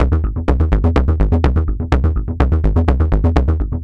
tx_synth_125_overdrive_C.wav